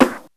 Snares
46___SNR.WAV